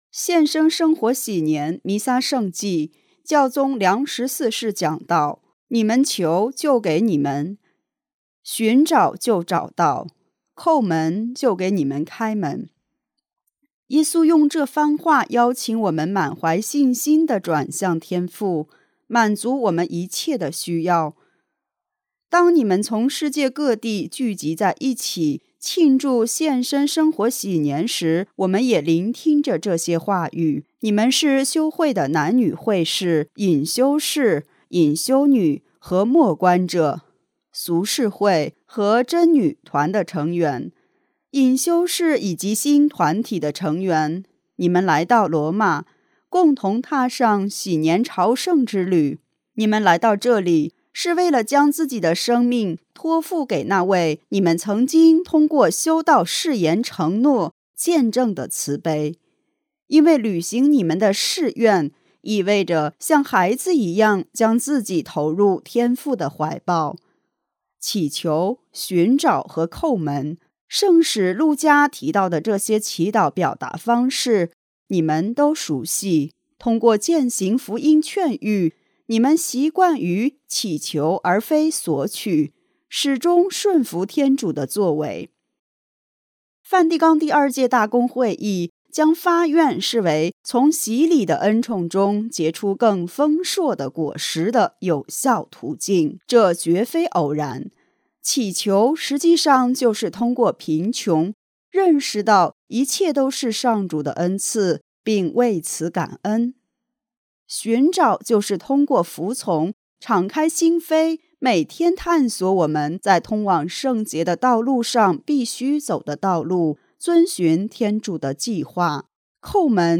献身生活禧年弥撒圣祭教宗良十四世讲道